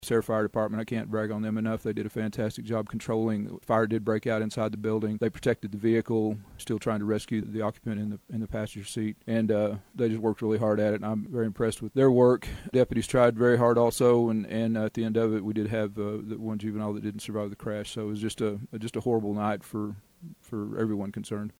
Beckham County Sheriff Derek Manning says the vehicle did catch fire and credits the Sayre Fire Department for their efforts in containing the blaze while they attempted to rescue the two girls.